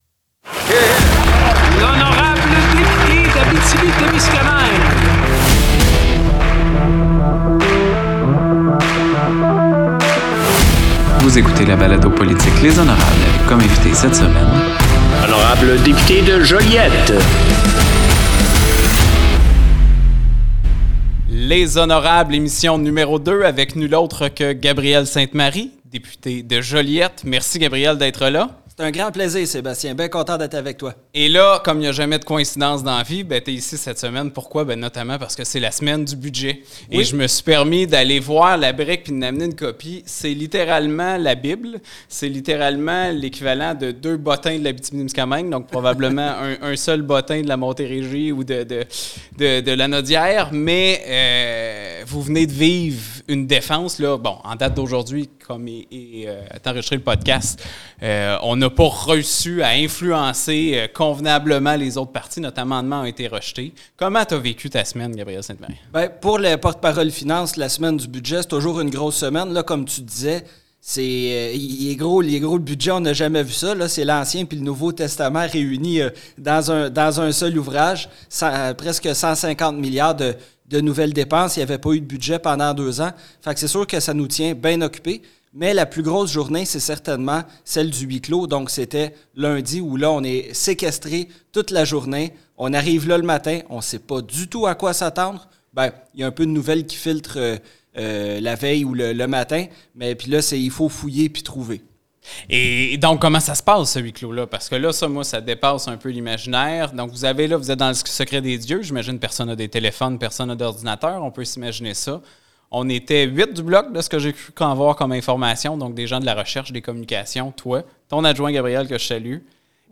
Dans une ambiance conviviale, Sébastien Lemire, député d’Abitibi-Témiscamingue accueille les membres de l’aile parlementaire du Bloc Québécois en mettant de l’avant le travail, le parcours, les motivations et les dossiers au cœur de leurs quotidiens. « Les honorables », c’est une conversation « entre élus » sous un angle professionnel, mais aussi personnel pour renseigner et valoriser le rôle des députés en mettant de l’avant leur personnalité et leur conviction.